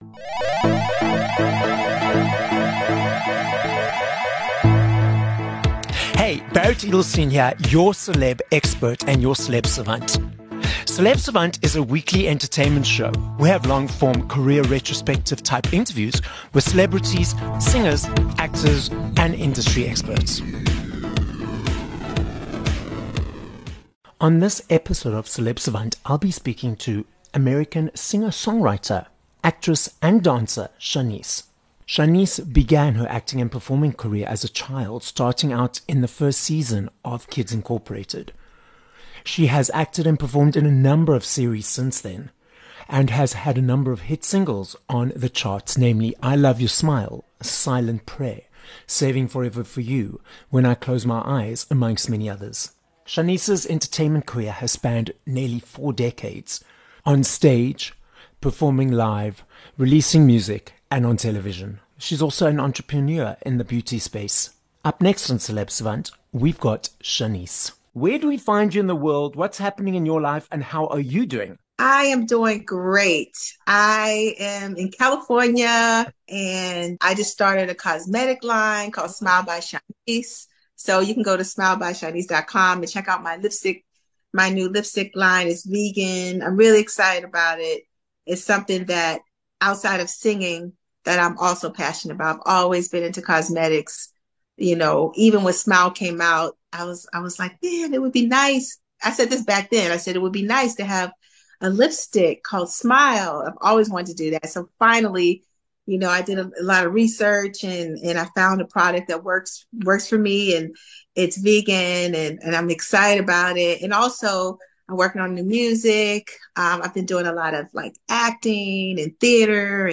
22 Feb Interview with Shanice